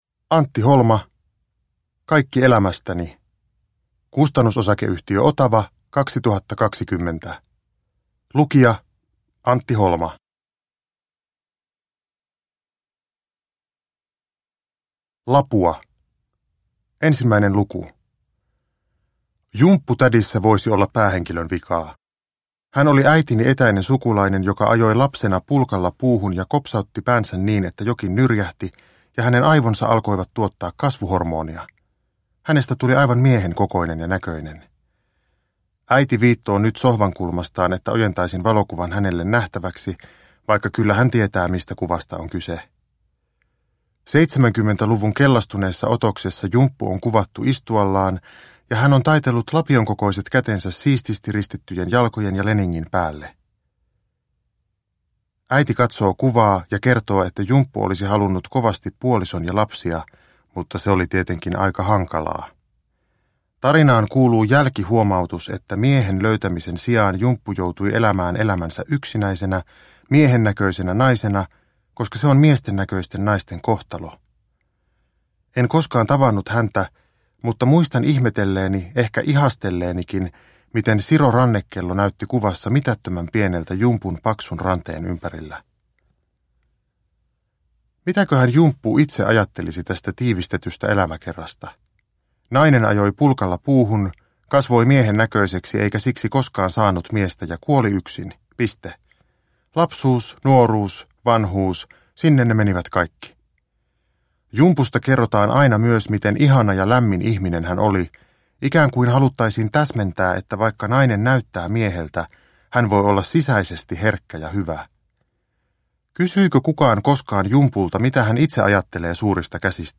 Kaikki elämästä(ni) – Ljudbok – Laddas ner
Uppläsare: Antti Holma